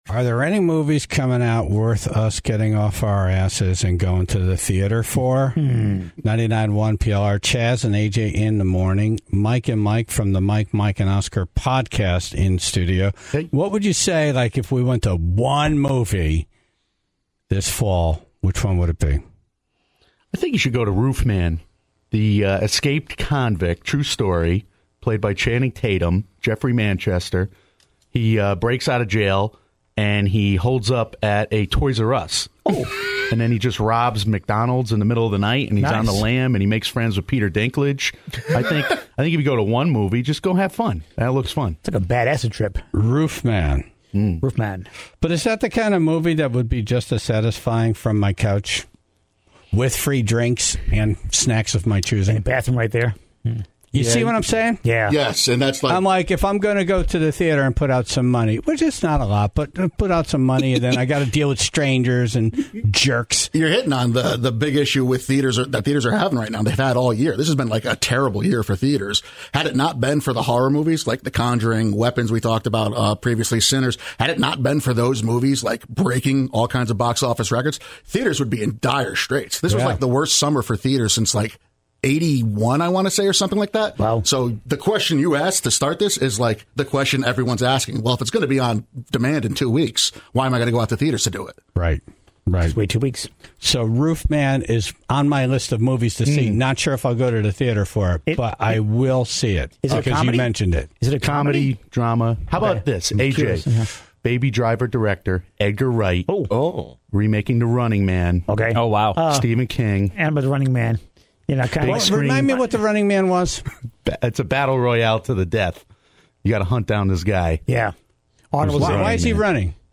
in studio to talk about the movies to be excited about for the fall.